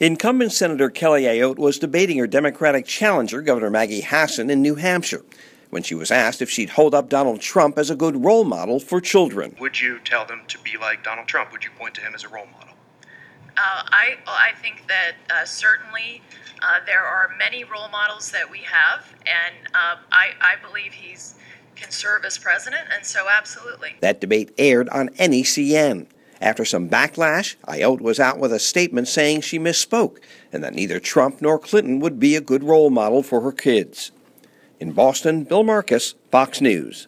New Hampshire Senator Kelly Ayotte (Courtesy of Fox News) (BOSTON) OCT 4 – A NEW HAMPSHIRE REPUBLICAN SENATOR IS DOING SOME DAMAGE CONTROL AFTER A STATEMENT SHE MADE AT MONDAY NIGHT’S DEBATE.